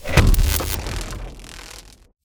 point_blowout.ogg.bak